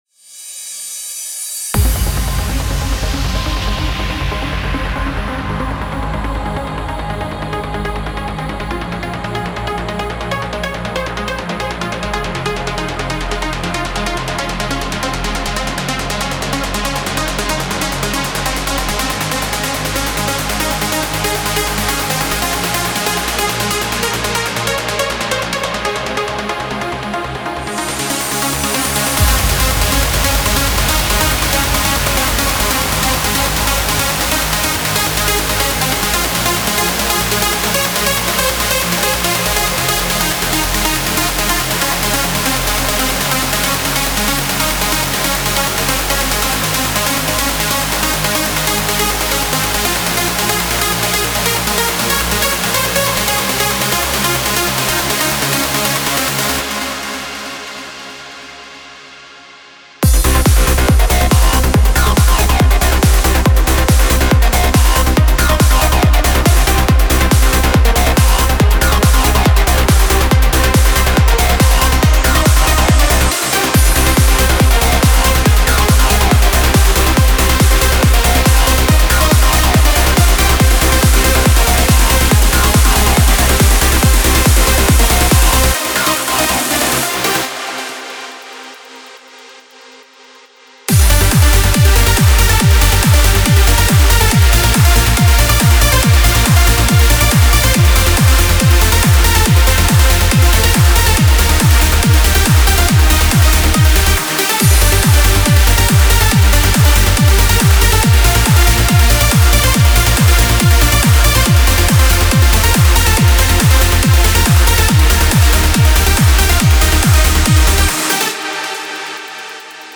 Genre: Trance Uplifting Trance
BPM 140 & Key Info Labelled on Each Kit Folder.